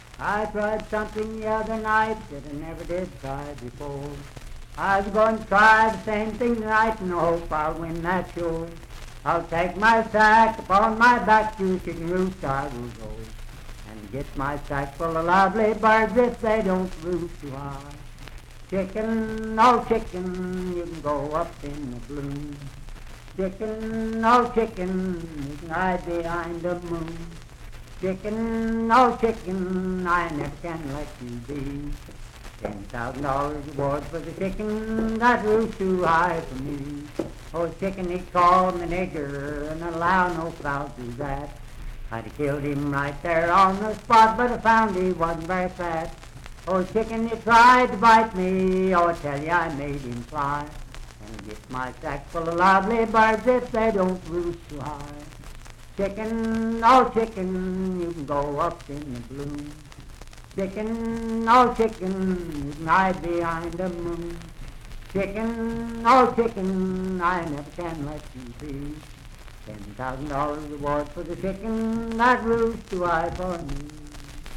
Unaccompanied vocal music and folktales
Minstrel, Blackface, and African-American Songs, Thieves and Thefts
Voice (sung)
Wood County (W. Va.), Parkersburg (W. Va.)